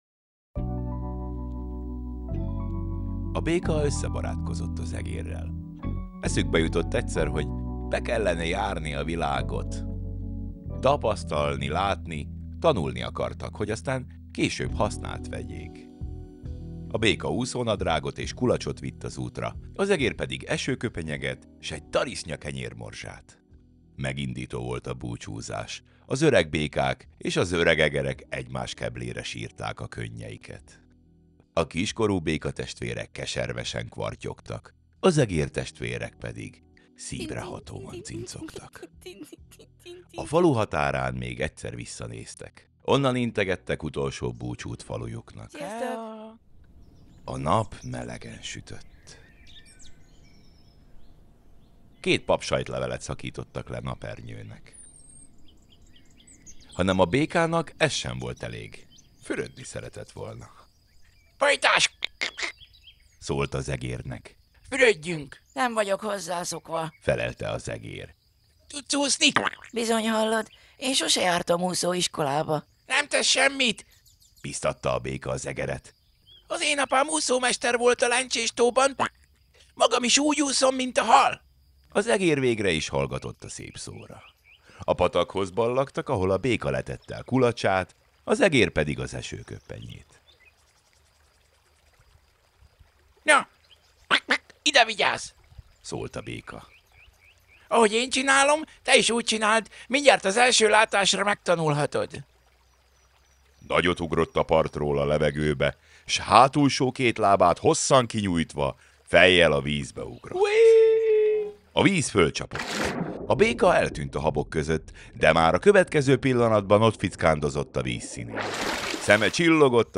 Most mesélőként térek vissza, hogy új életre keltsem ezeket a történeteket – nem csak gyerekeknek!